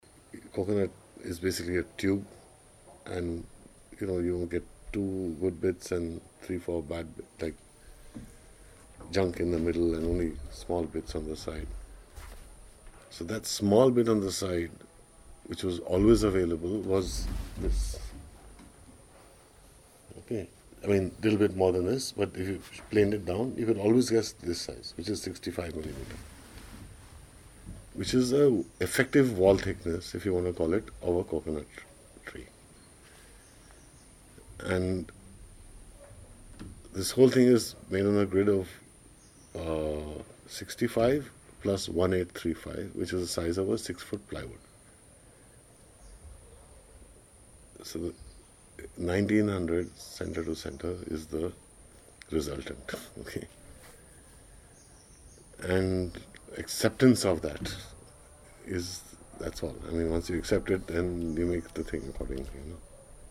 Excerpts from a conversation